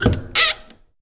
Other Sound Effects / RPG Sounds Pack 6